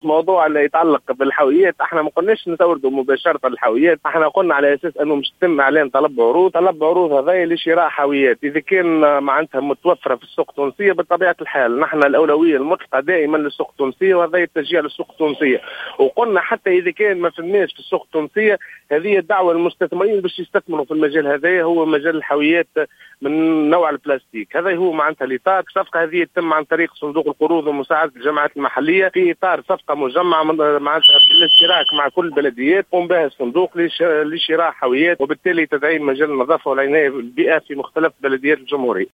كما كان لكاتب الدولة لدى وزير الشؤون المحلية، مكلف بالبيئة، شكري بلحسن، التوضيح التالي في اتصال هاتفي بـ "الجوهرة اف أم":